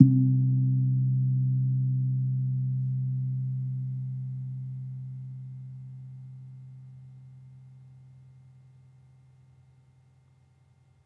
Gong-C2-f.wav